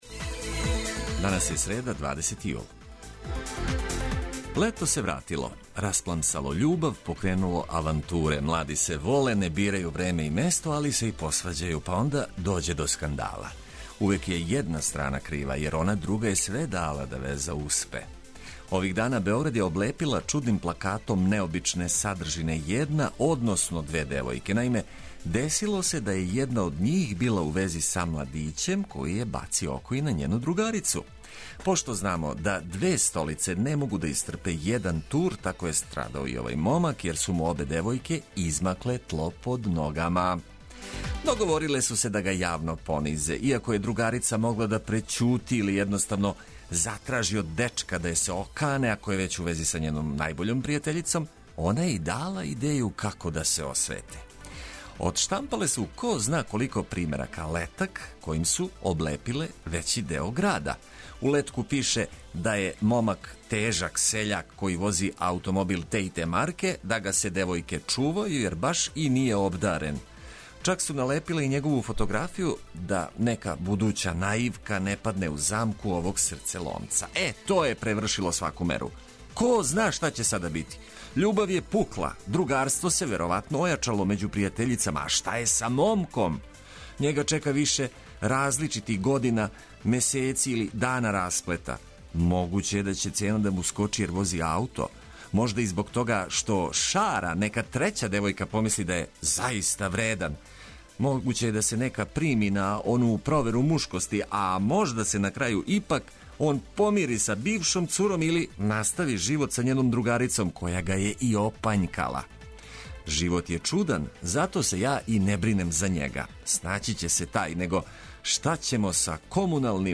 Лагано буђење уз музику и приче од користи за све који нас ослушкују. Зашто бициклисти не могу да користе лифт код Моста на Ади, и колико ће потрајати чекање на полагање возачких испита, само су неке од тема о којима ћемо говорити током јутра.